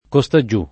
costaggiù [ ko S ta JJ2+ ] avv.